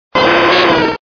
Fichier:Cri 0059 DP.ogg